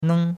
neng1.mp3